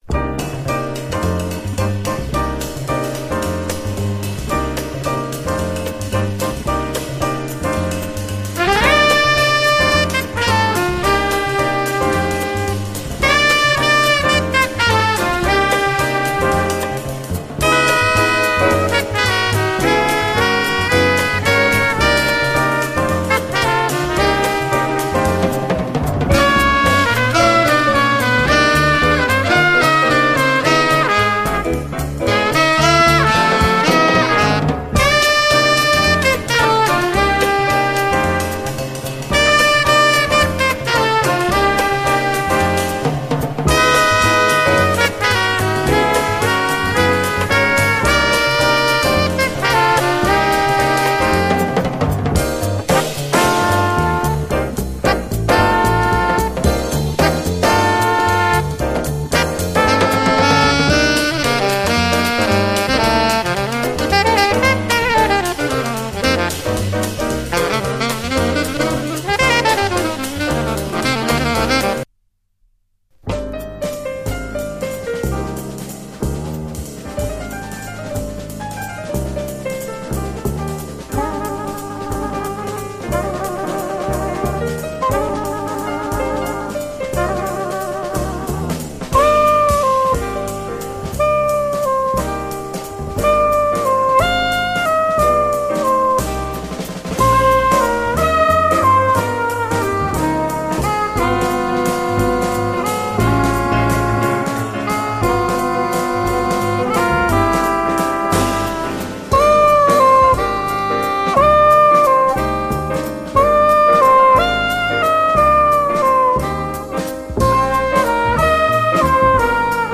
クラブ・ジャズ的にもよいです！
端正な佇まいの演奏は、決して頭でっかちにならず、気品ある美しさをひたすらに追求！軽やかなボッサ・リズムのジャズ・ダンサー